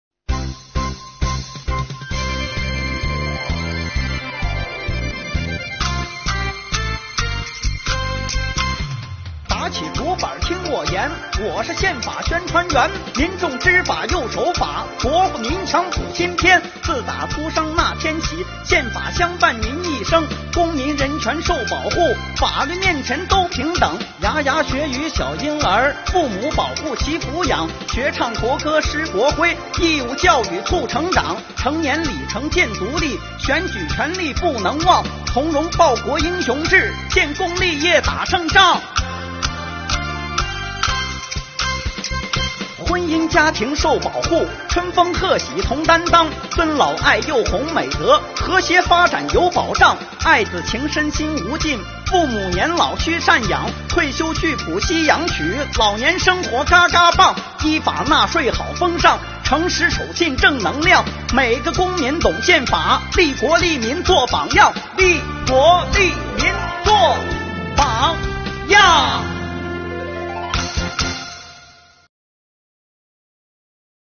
动漫 | 快板说宪法